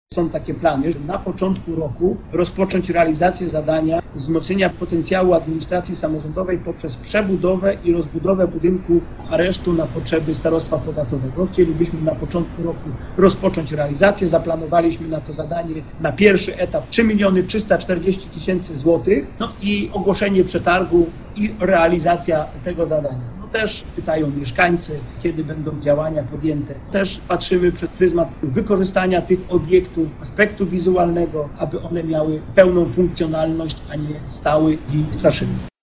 Na początku 2022 roku ma ruszyć pierwszy etap adaptacji aresztu na potrzeby starostwa. Mówi starosta niża